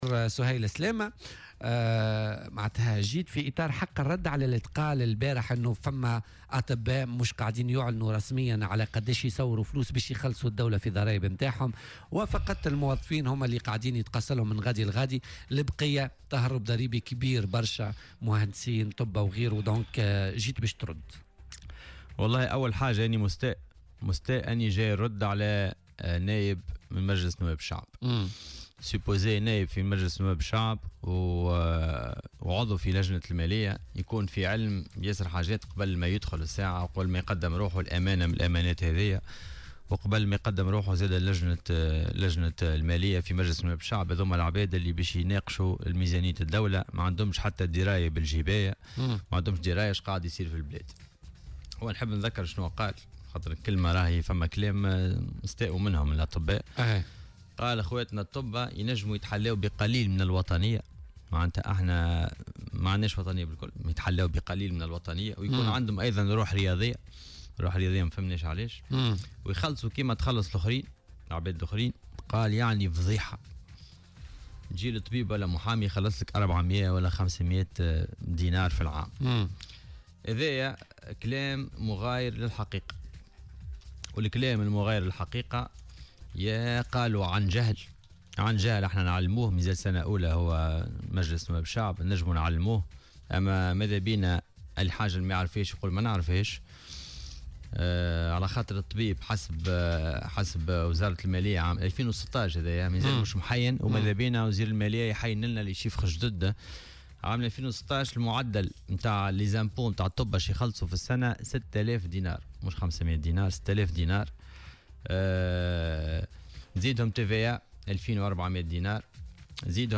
وأضاف في مداخلة له اليوم في برنامج "بوليتيكا" على "الجوهرة أف أم" أنه يستغرب مثل هذه التصريحات من نائب بالبرلمان وعضو في لجنة المالية الوقتية، ومن ما اعتبره "قلة دراية ومعرفة".